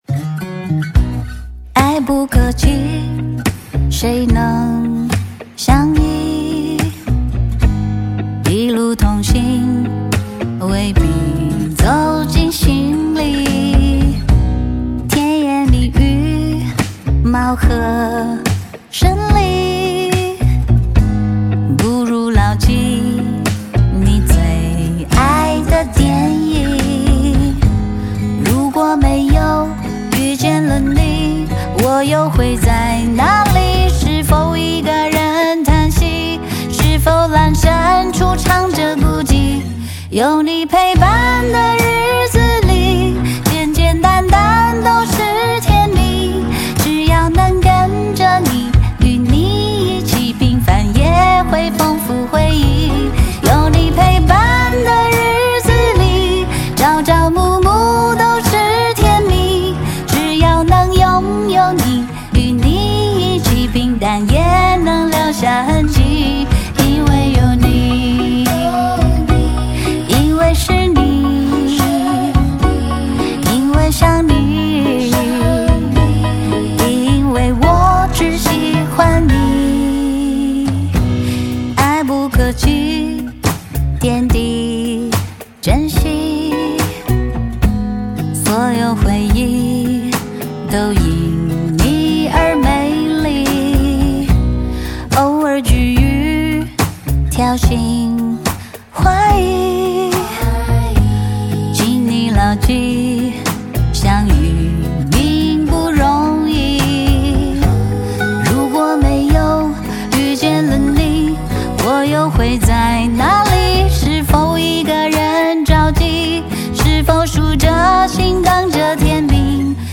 每一首旋律都让人忍不住随节奏恣意摇摆
这张专辑刻意以鲜明的复古色彩与怀旧风格，做为音乐基调环绕每一首歌曲命题